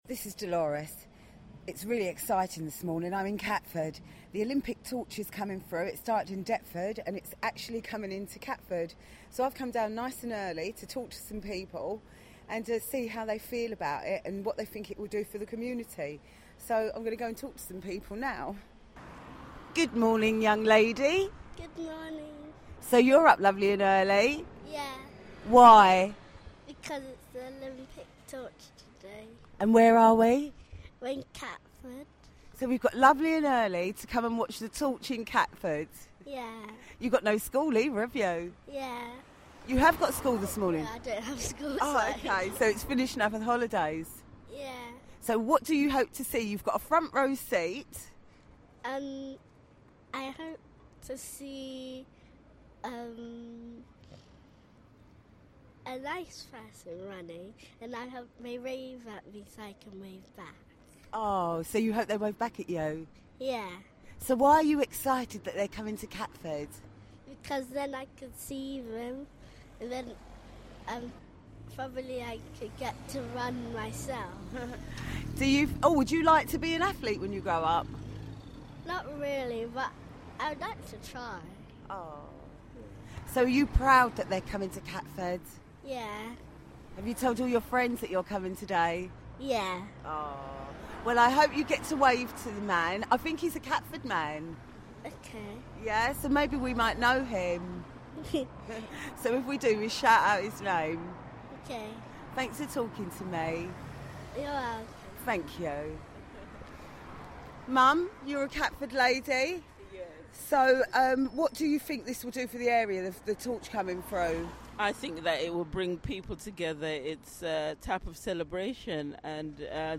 Catford comes out for the Olympic Torch.